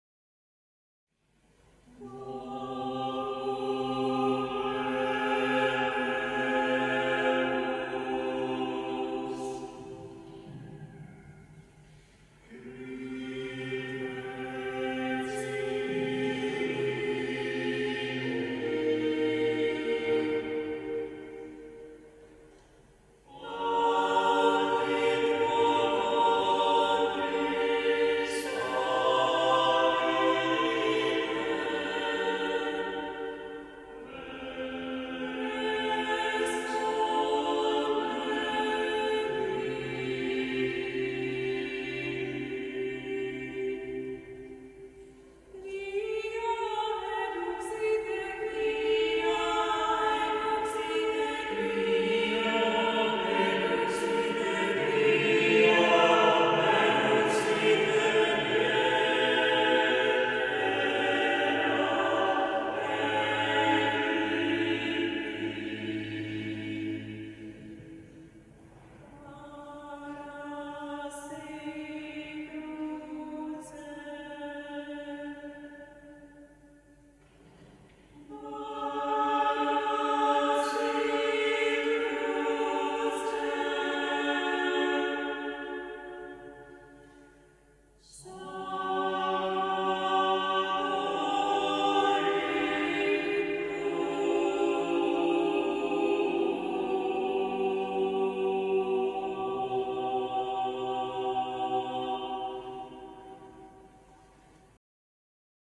Lenten chorale
4-SATB